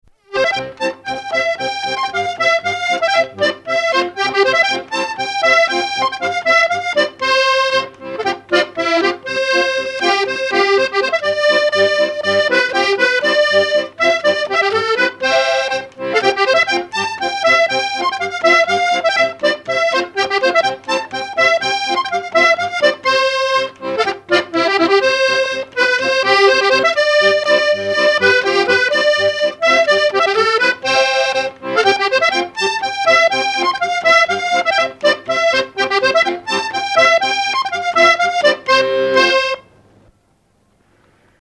Enregistrement original de l'édition sur disque vinyle
musicien sarthois, musique pour les assauts de danse et le bal.
accordéon(s), accordéoniste ; musique traditionnelle
danse : quadrille
Pièce musicale inédite